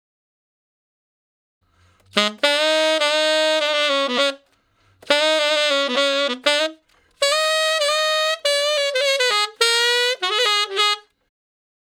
068 Ten Sax Straight (Ab) 13.wav